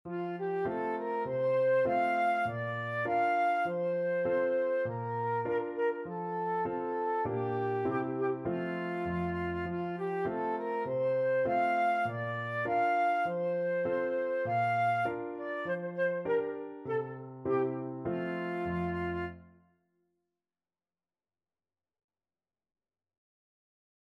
Flute version
4/4 (View more 4/4 Music)
Moderato